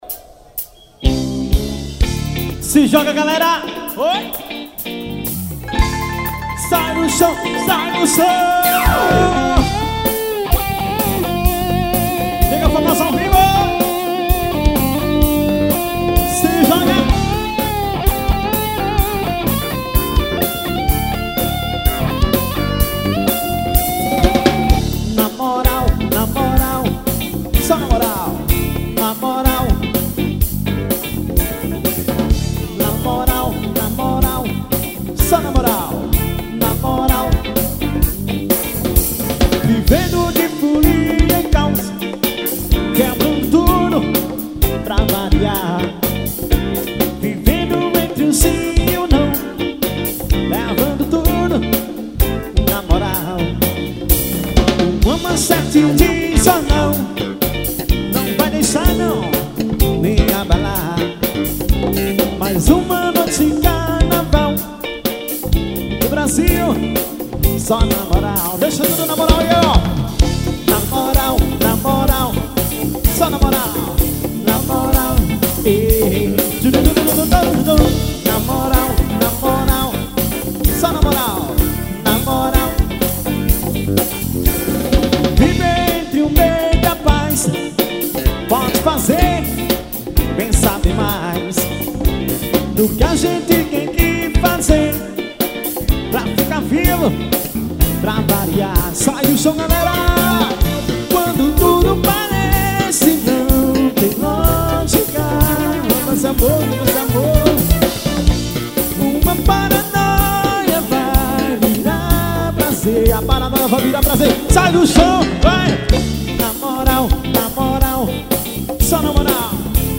Gravei o baixo.